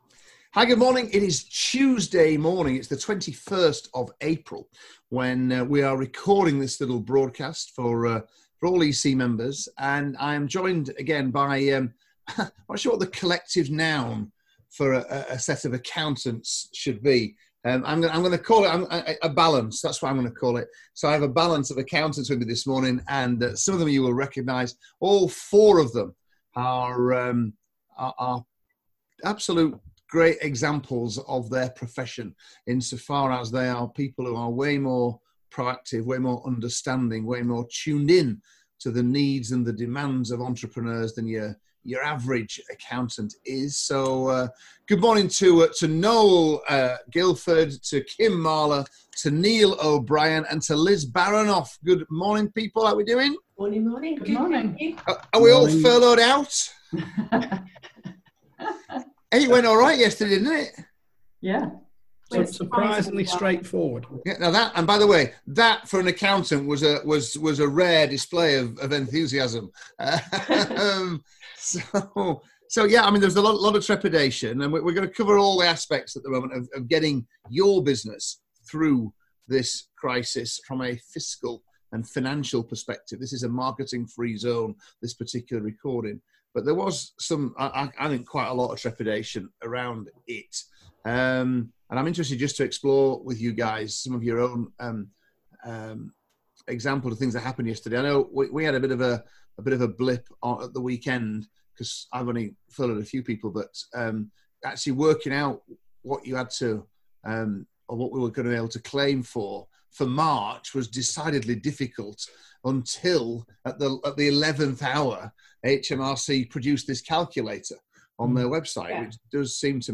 Great advice from the Entrepreneurs Circle and four accountants reviewing furlough submission with HMRC.